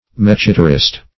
Mechitarist \Mech"i*tar*ist\, n.
mechitarist.mp3